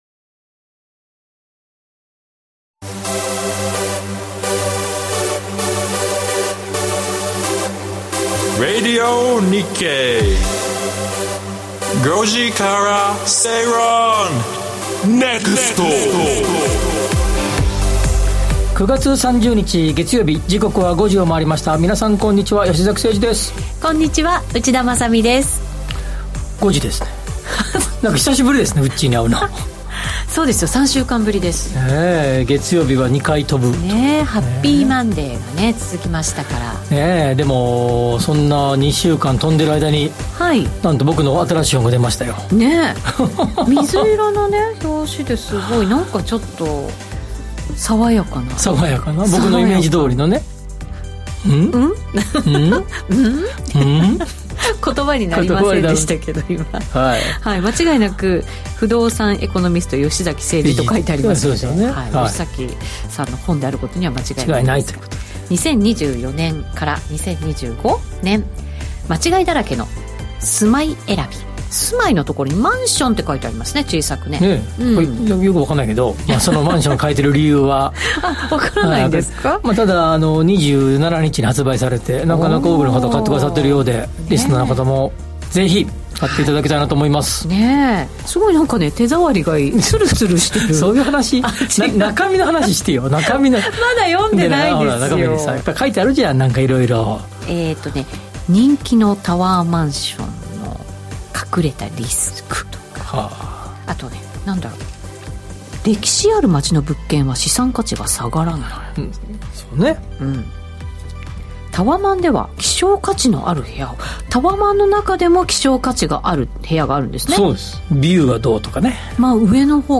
ビジネスマンや個人投資家に向けて 「景気・経済動向をわかりやすく解説する月曜日」 明日の仕事でつかえるネタ、今夜の食事時に話したいネタを、人生を豊かにするネタをざっくばらんな雰囲気でお届けします！